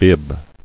[bib]